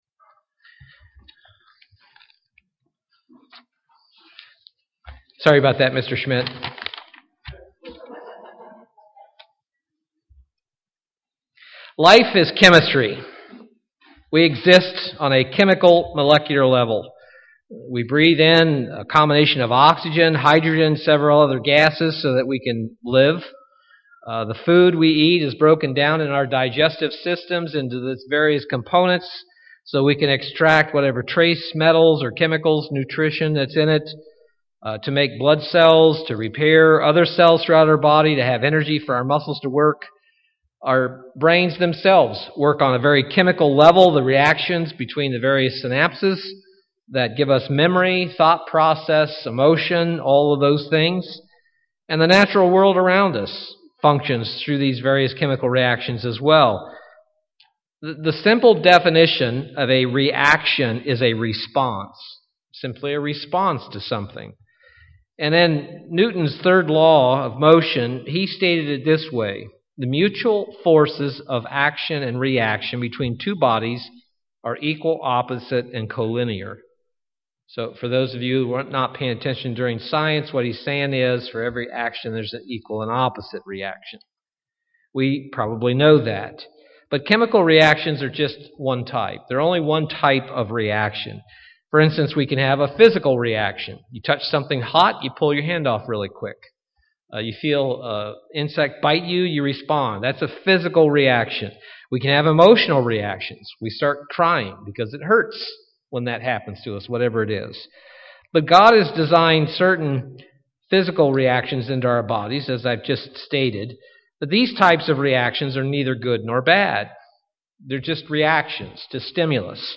Given in Milwaukee, WI
Print Christians are called to be visionary not reactionary UCG Sermon Studying the bible?